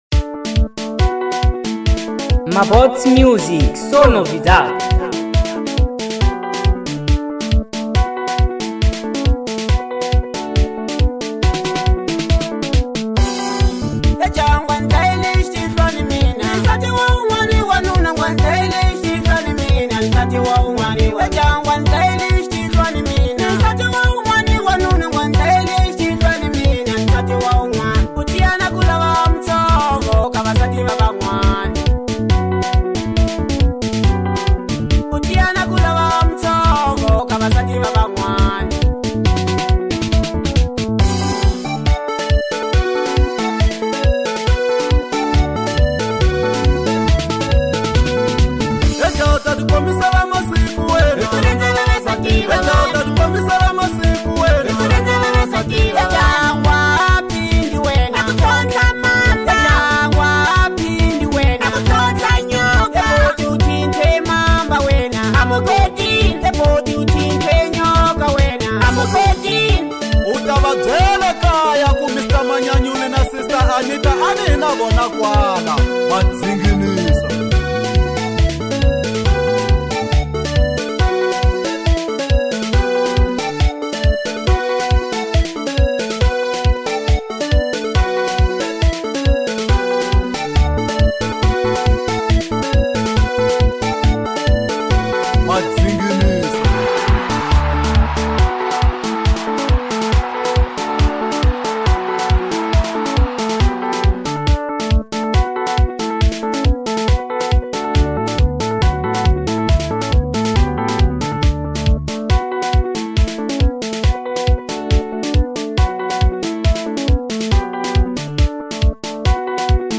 04:09 Genre : Marrabenta Size